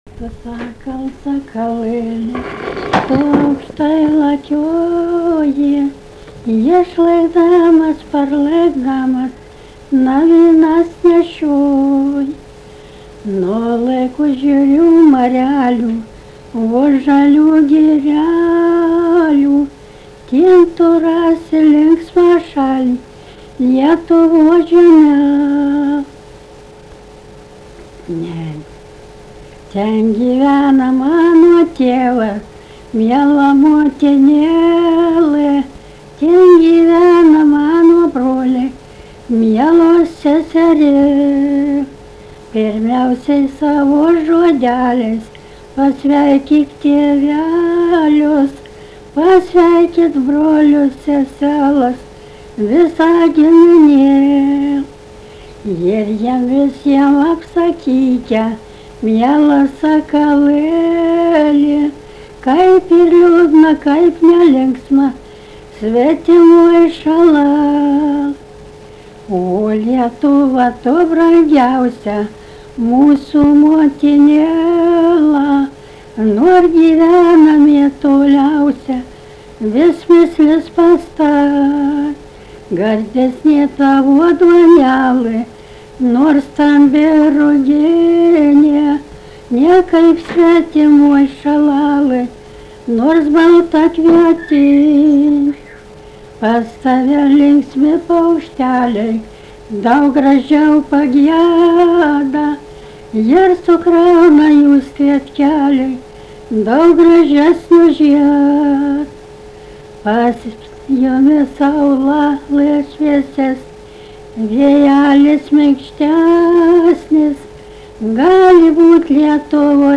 daina
Druskeliškės
vokalinis